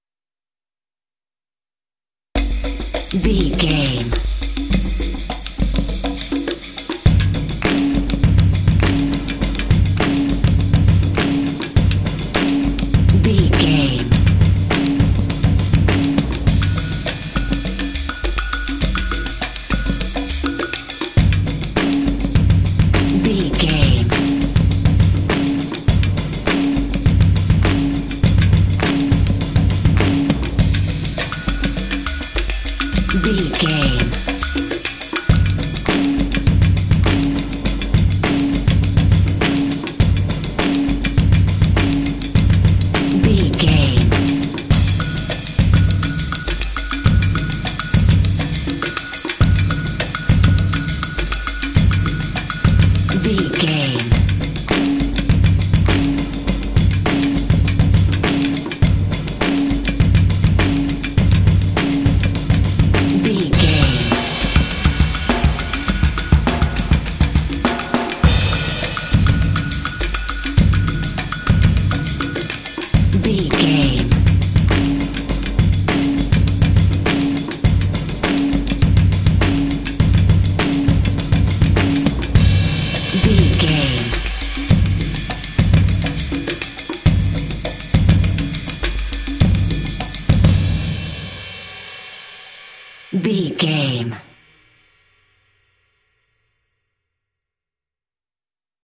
Epic / Action
Atonal
groovy
intense
driving
energetic
drums
percussion